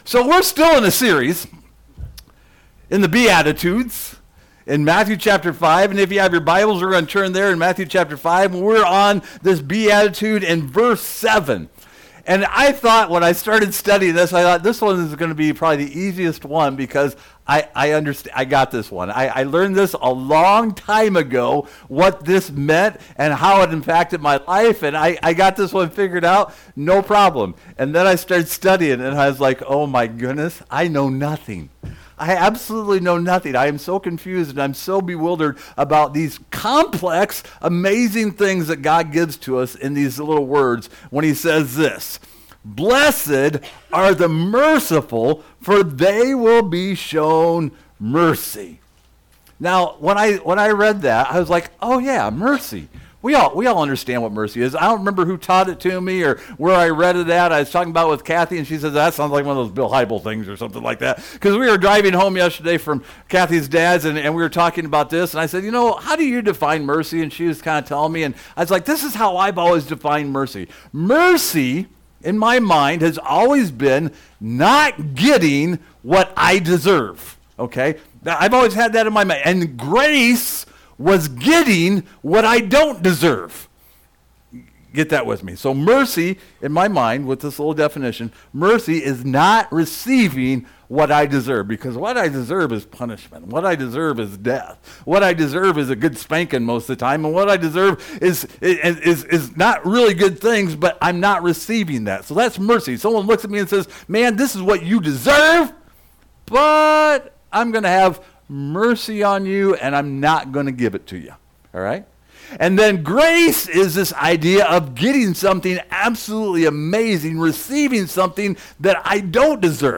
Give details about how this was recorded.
Jude 22-25 Service Type: Sunday Morning Through the parables of Jesus we explore the meaning of mercy.